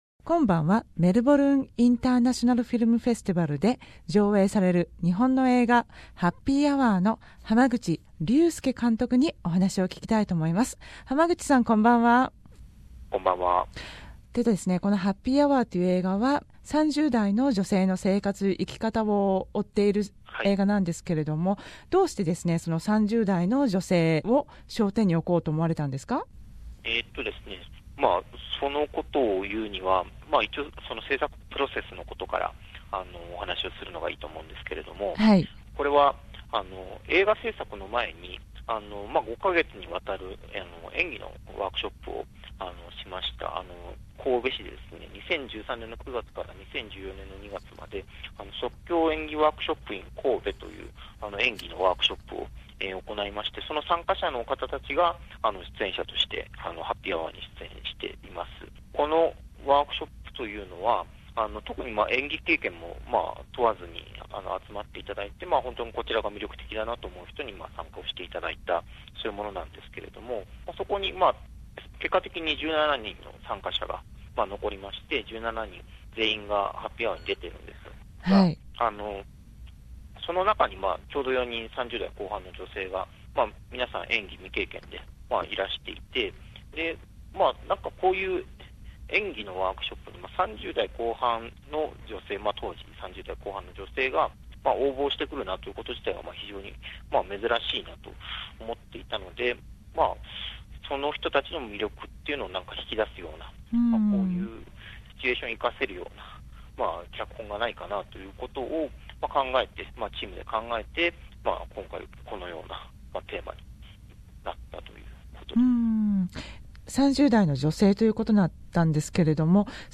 メルボルン国際映画祭で上映される映画『ハッピーアワー』の濱口竜介監督にインタビューしました。